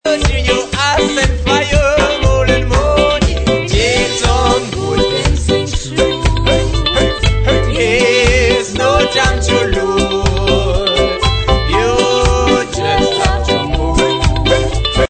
rock steady